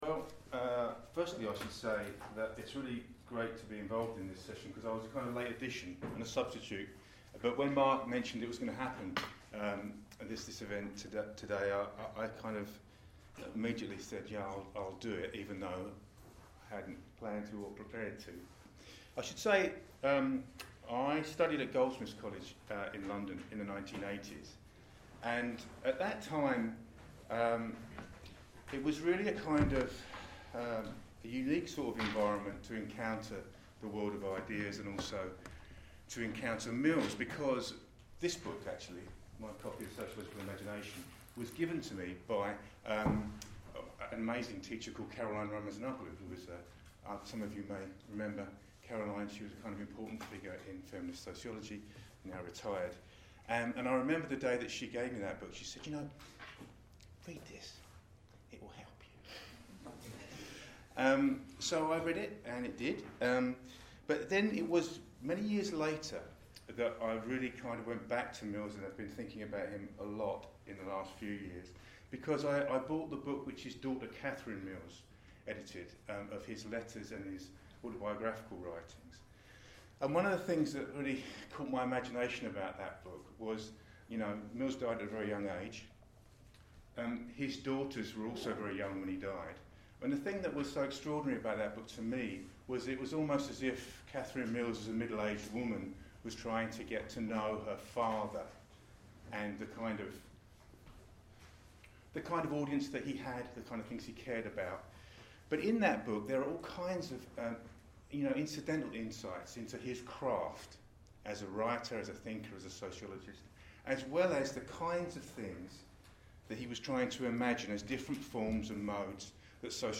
talk
from the C Wright Mills session I organised at the BSA conference in Leeds